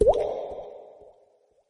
Water Drop Sound ringtone free download